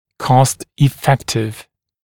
[kɔst-ɪ’fektɪv][кост-и’фэктив]помогающий сэкономить